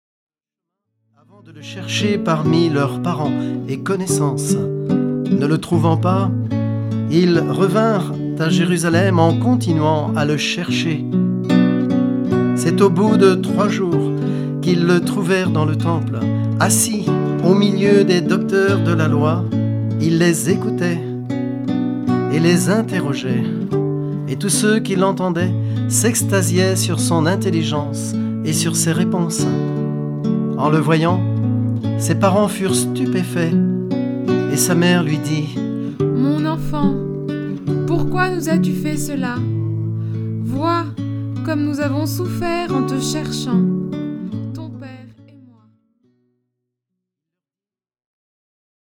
Chaque mystère est accompagné de chants pour la louange
Format :MP3 256Kbps Stéréo